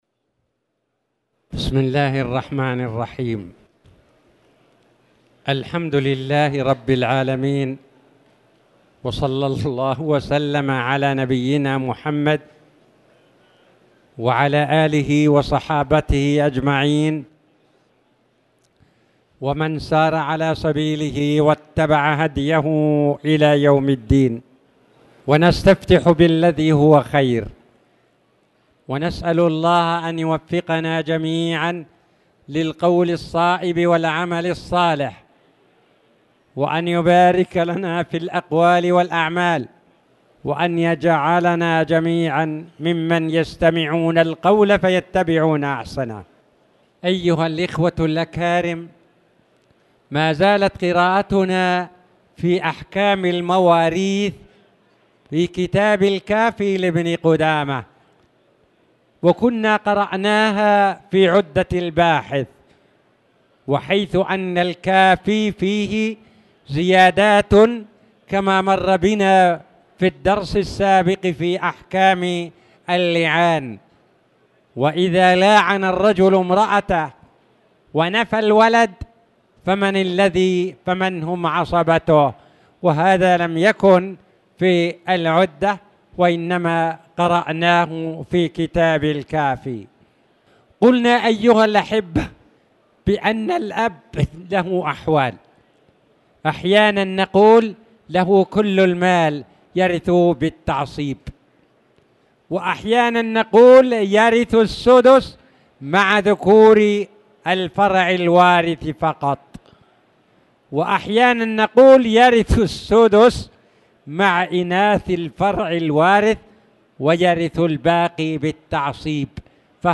تاريخ النشر ٨ شوال ١٤٣٧ هـ المكان: المسجد الحرام الشيخ